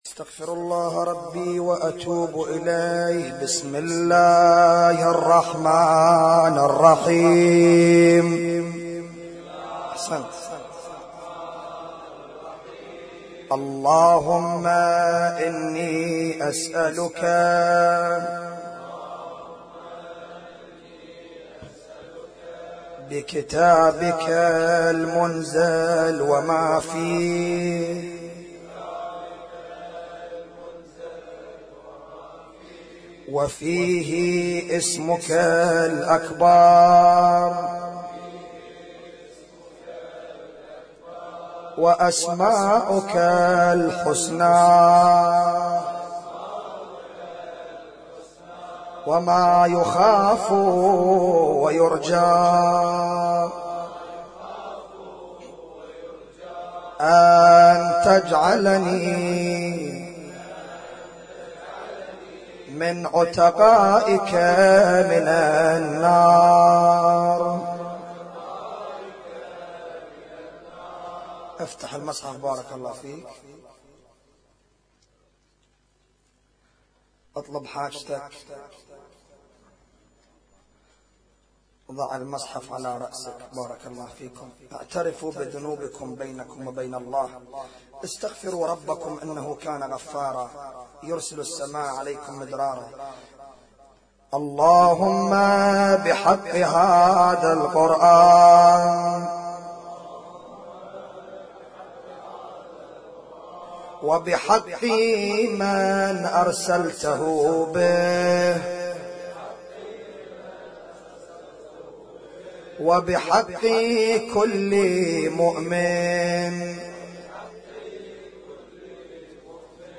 قائمة المـكتبة الصــوتيه الاحياء ليلة 21 من رمضان 1438
اسم التصنيف: المـكتبة الصــوتيه >> الادعية >> ادعية ليالي القدر